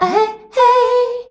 AHEHEY  G.wav